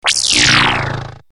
fire_projector.mp3